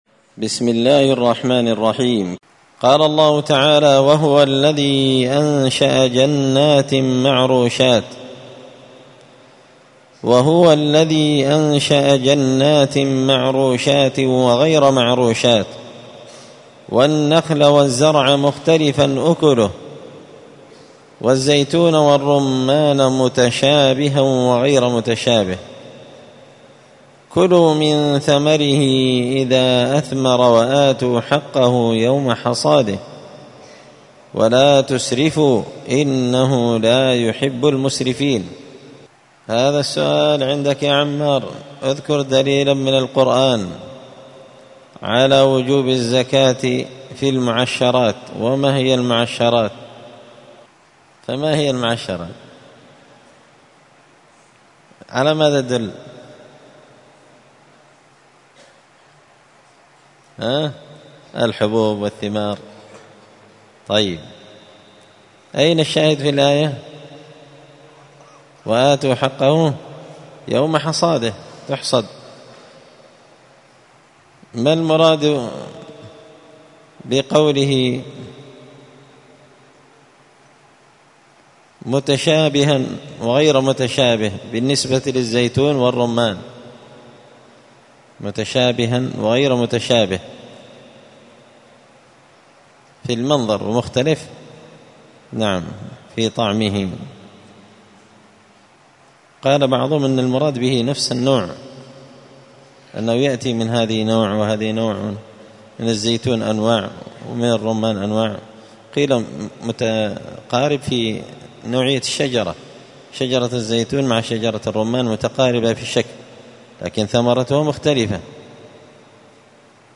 الدروس اليومية
مسجد الفرقان قشن_المهرة_اليمن